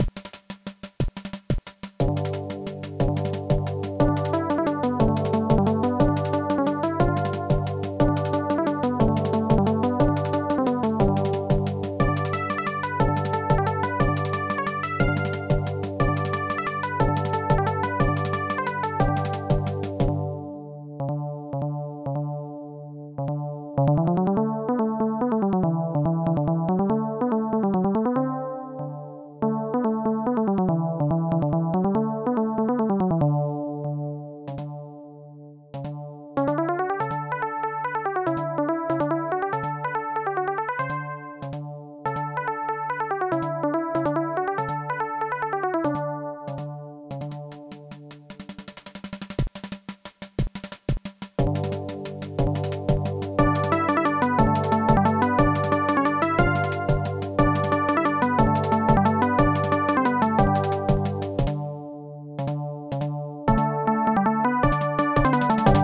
AdLib MUS
GIGUE.mp3